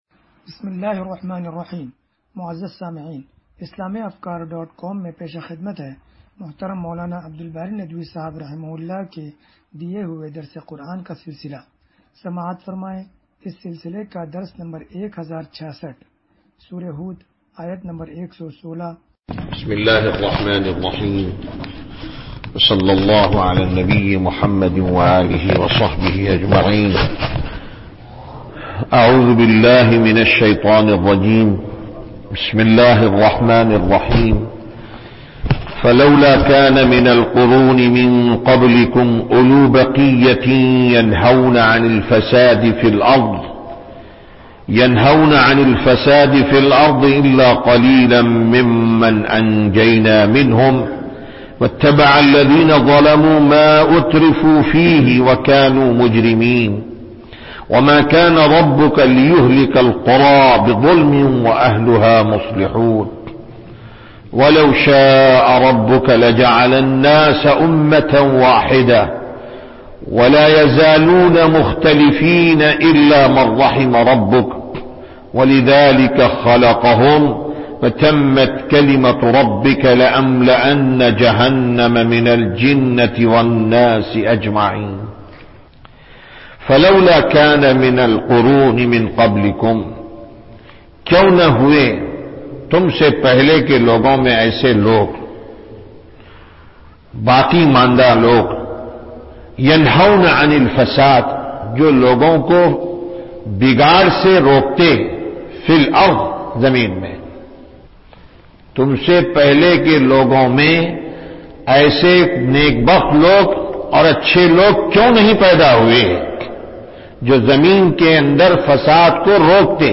درس قرآن نمبر 1066